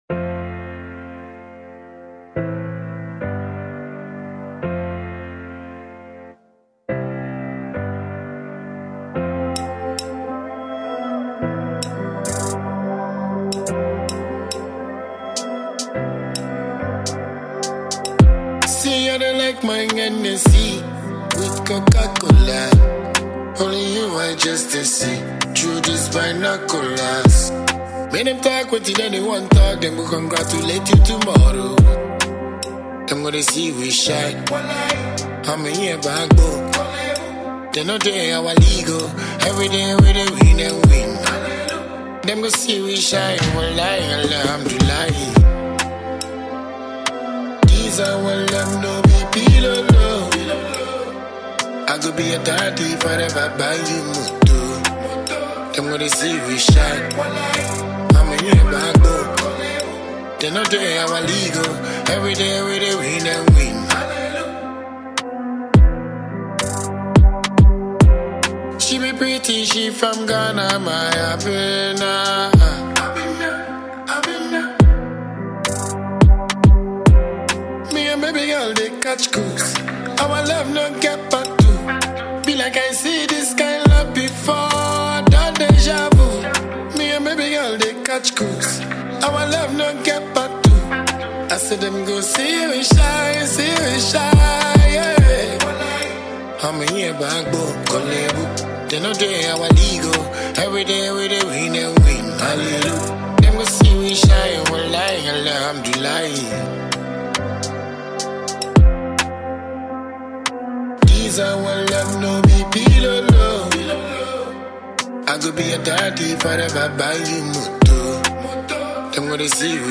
a dancehall god in the Ghana music industry